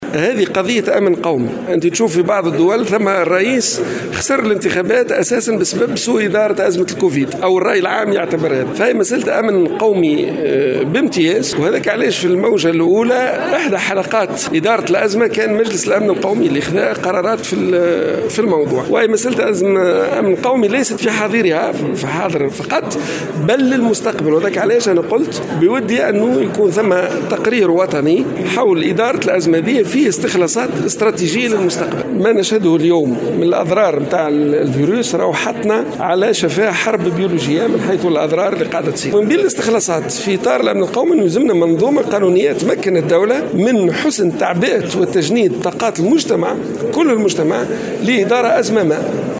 وصف وزير الصحة الأسبق عبد اللطيف المكي، في تصريح للجوهرة أف أم، اليوم الجمعة، جائحة كوفيد 19 بقضية أمن قومي داعيا لإعداد تقرير وطني حول إدارة أزمة الكورونا يضم أبرز الاستخلاصات الاستراتيجية للاستفادة منها في المستقبل.
ودعا المكي على هامش ندوة علمية، انتظمت اليوم الجمعة في مدينة العلوم بتونس، إلى إرساء منظومة قانونية تُمكن الدولة من حُسن تعبئة وتجنيد طاقات كل المجتمع لإدارة أي أزمة قادمة مهما كان نوعها.